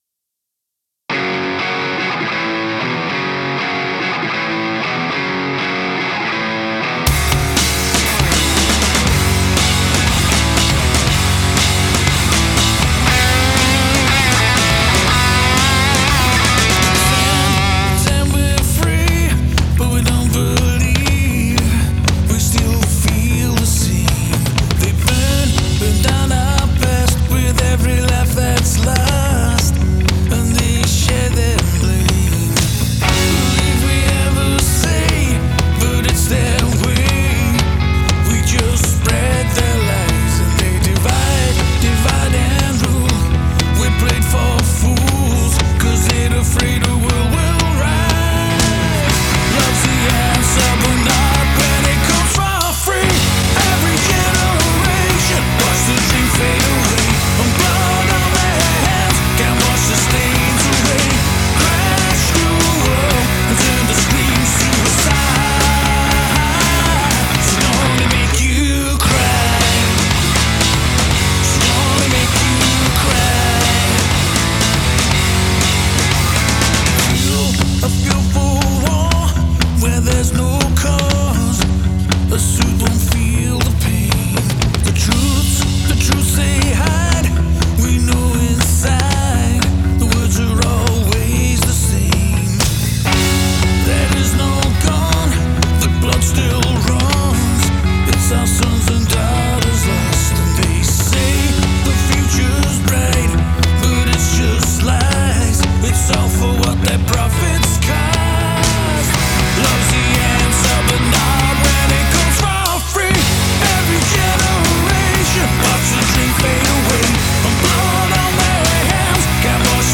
More of an alternative feel